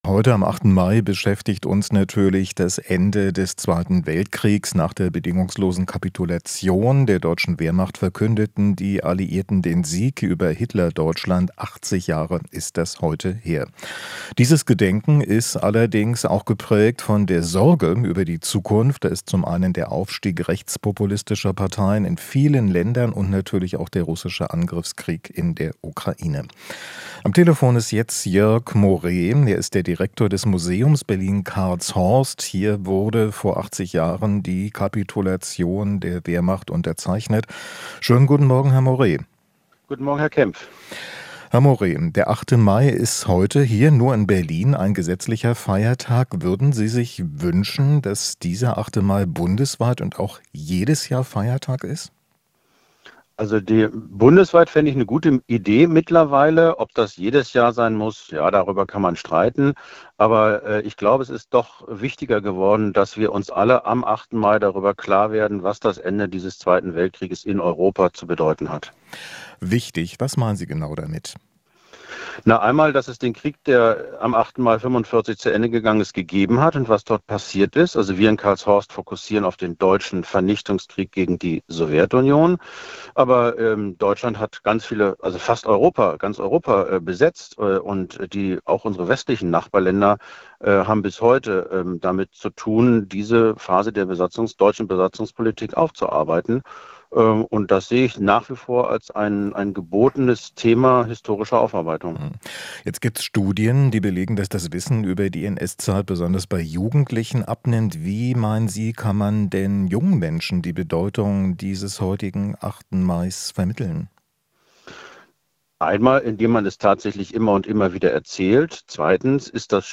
Interview - Museumsdirektor: Erinnern an den Krieg auch heute noch wichtig